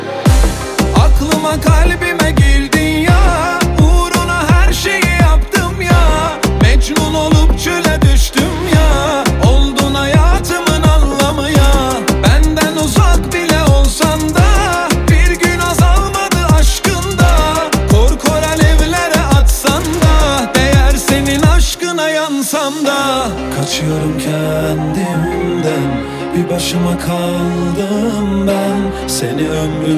Жанр: Поп / Турецкая поп-музыка